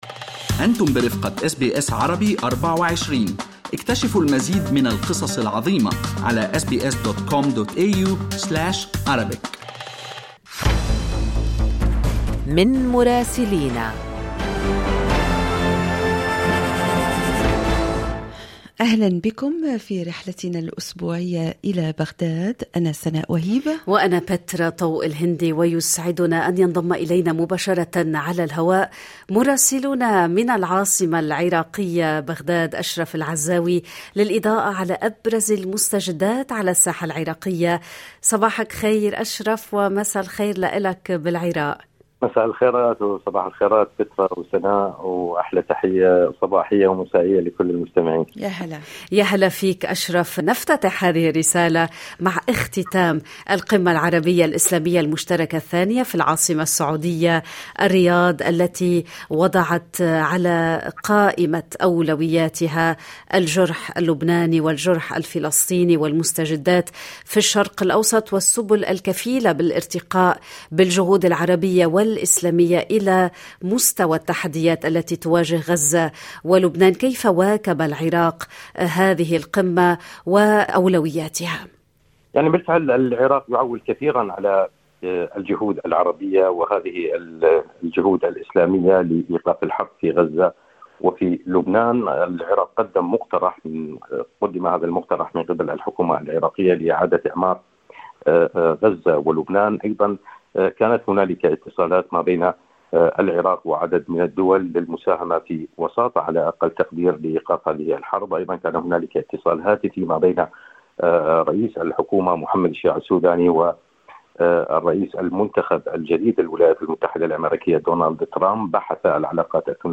يمكنكم الاستماع إلى تقرير مراسلنا من بغداد بالضغط على التسجيل الصوتي أعلاه.